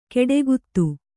♪ keḍeguttu